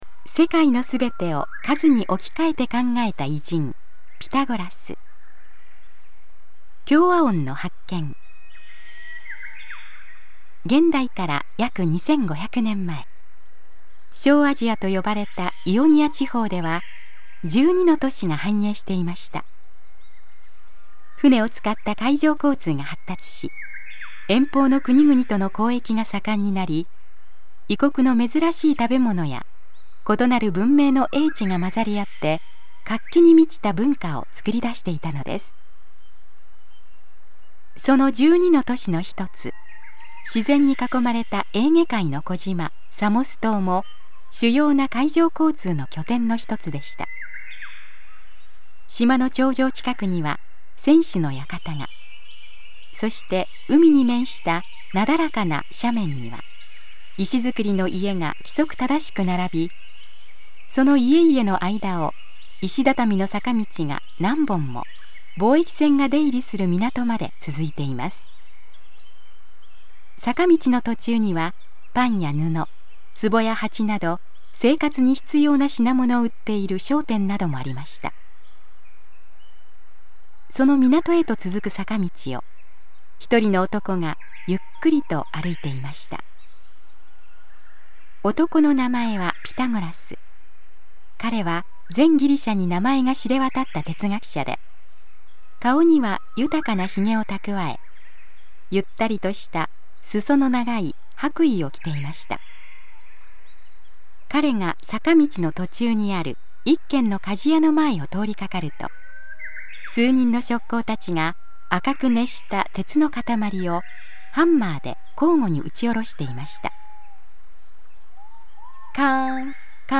森の中の鳥のさえずりなどの自然音がバックに流れる中、独自のコンテンツや価値ある講演などの音声を、１倍速から無理なく段階的に高速再生し、日々音楽のように楽しく聴くことによって、年齢に関係なく潜在意識を “脳力全開”させていくシステムです
歴史上の偉人たちの話を、わかりやすい文章と穏やかな語りでお伝えする朗読ＣＤです